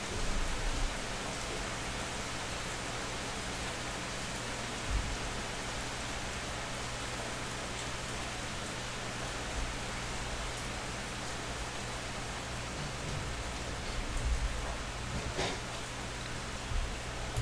描述：在麦德林的区域大道上下雨
Tag: 风暴 天气